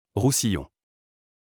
Roussillon (UK: /ˈrsijɒn/ ROO-see-yon,[1] US: /ˌrsiˈjn/ ROO-see-YOHN,[2] French: [ʁusijɔ̃]
Fr-Roussillon.wav.mp3